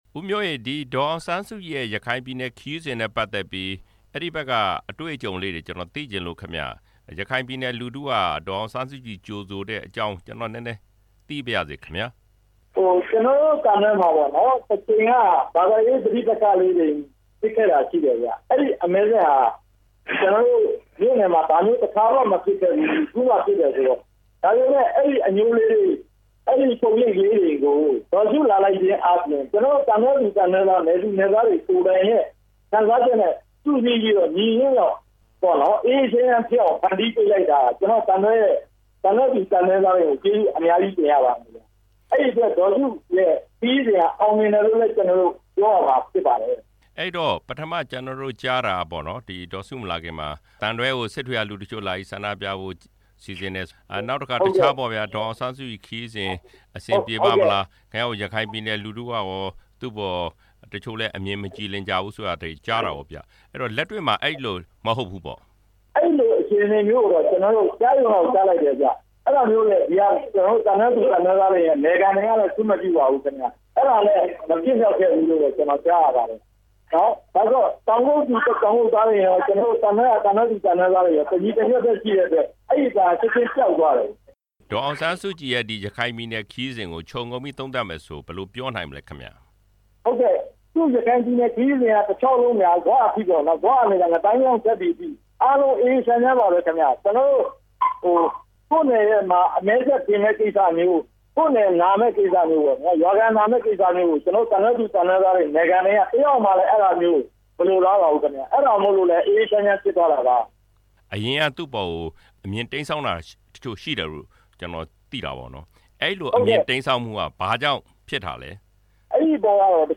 ဒေါ်အောင်ဆန်းစုကြည်ရဲ့ ရခိုင်ခရီးစဉ် အကျိုးသက်ရောက်မှု မေးမြန်းချက်